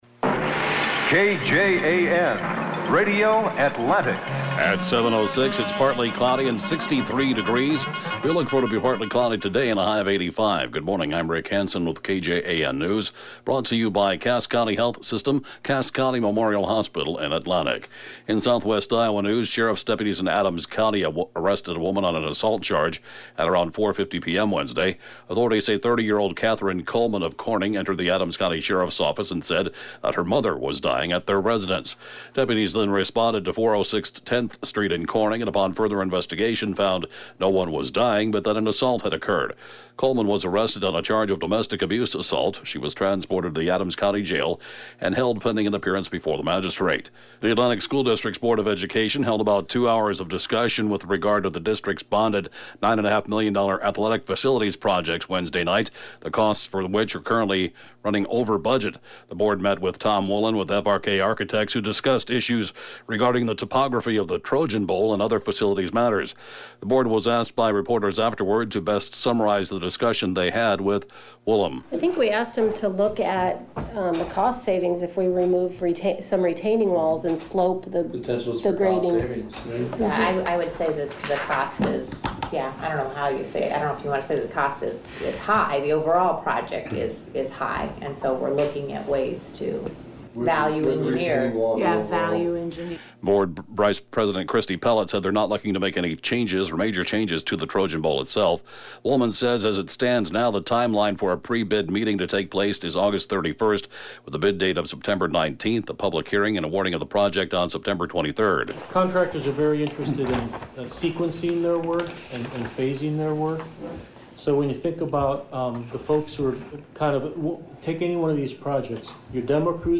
KJAN News